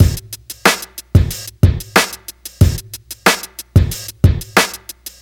• 92 Bpm 00s Rap Drum Loop Sample F Key.wav
Free breakbeat sample - kick tuned to the F note. Loudest frequency: 1816Hz
92-bpm-00s-rap-drum-loop-sample-f-key-LFz.wav